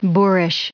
1461_boorish.ogg